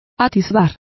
Complete with pronunciation of the translation of peeps.